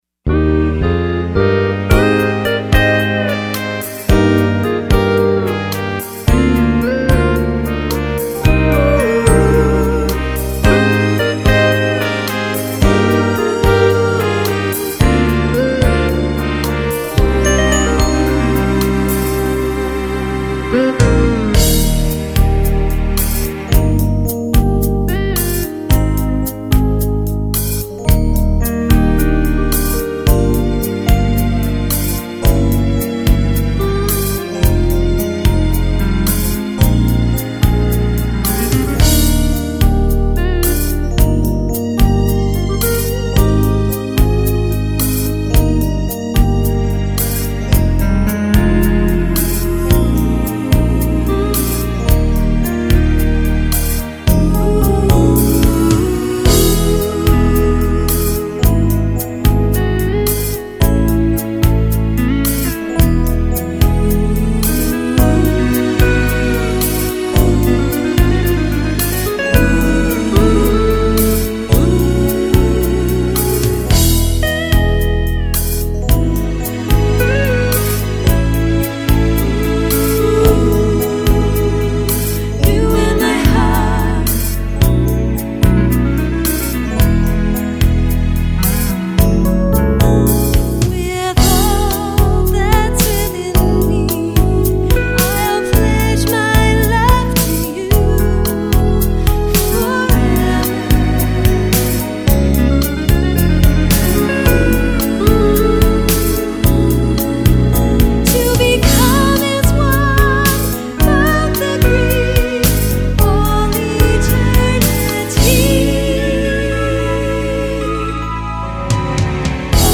Background Vocals